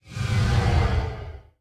dronego2.wav